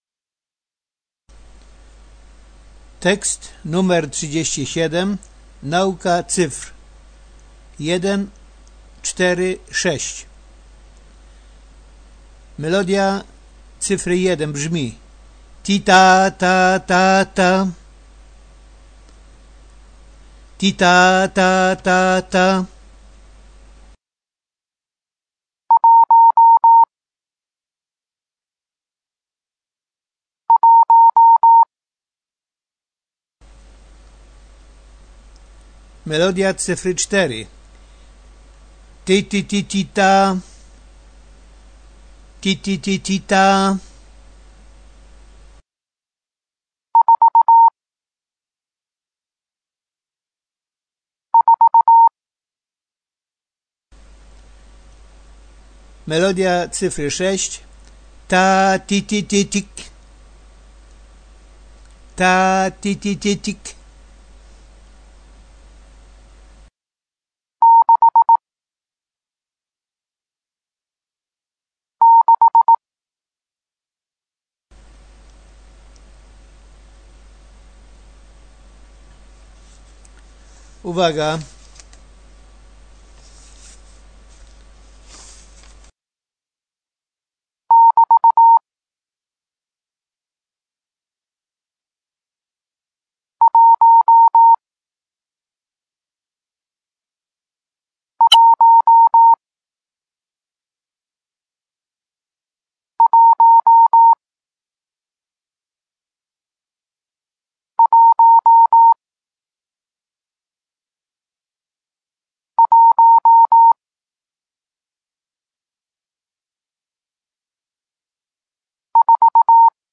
Tekst 37 – Nauka cyfr 1 4 6 Melodia cyfty: 1 ti-taaa-taaa-taaa-taaa 4 ti-ti-ti-ti-taaa 6 taaa-ti-ti-ti-tit